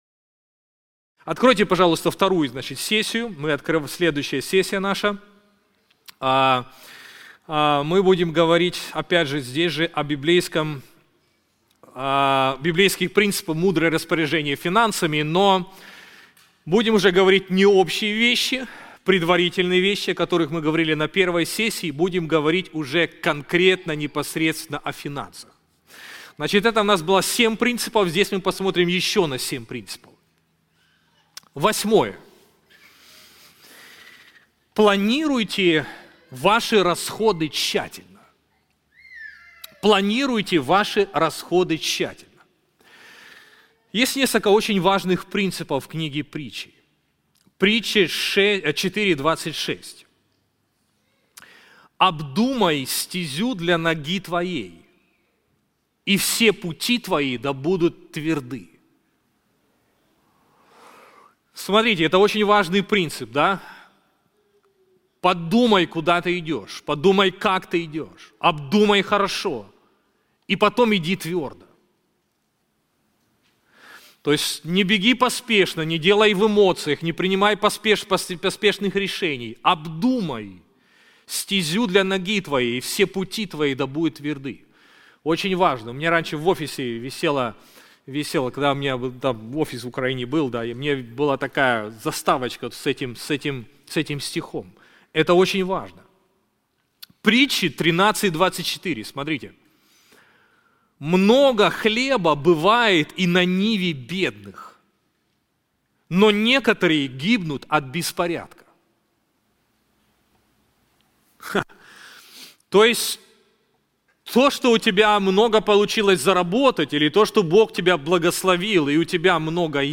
Мы подготовили специальный семинар о библейских принципах управления финансами.